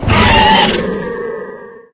sounds / monsters / bloodsucker / hit_3.ogg
hit_3.ogg